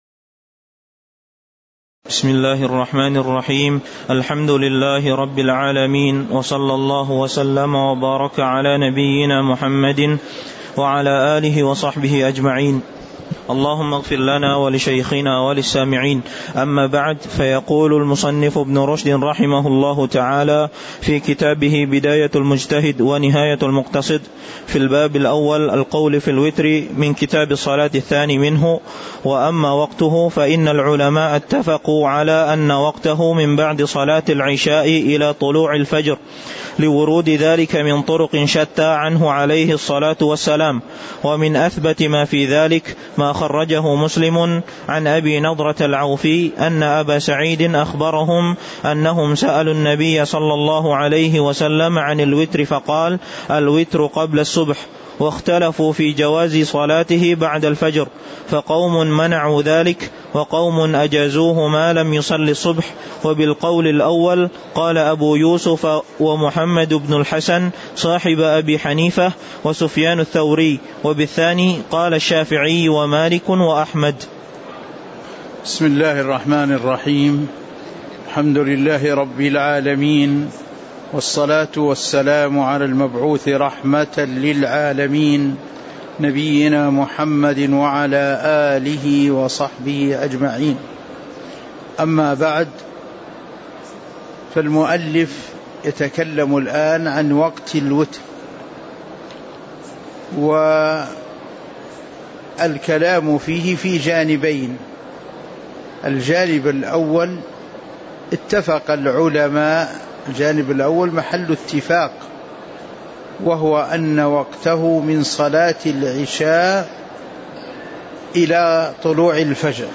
تاريخ النشر ١٧ جمادى الأولى ١٤٤٤ هـ المكان: المسجد النبوي الشيخ